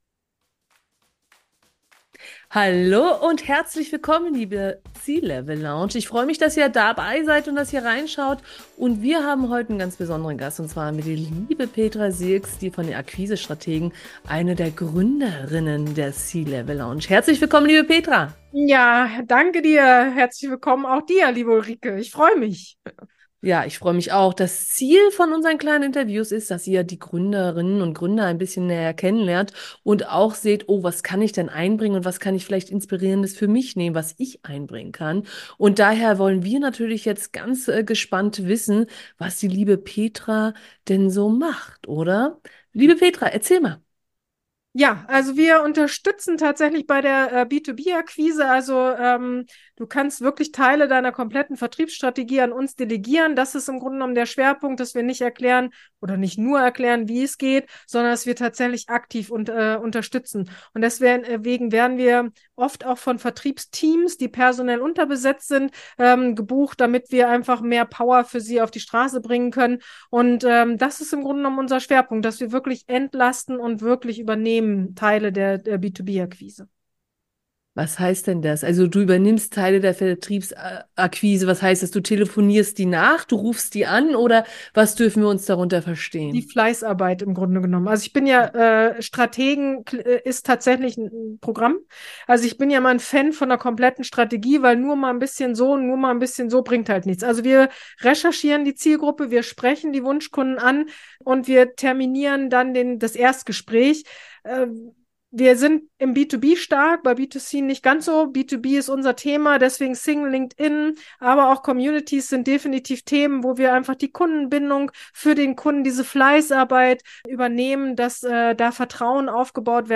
Unternehmer-Community: C-Level Lounge | Interview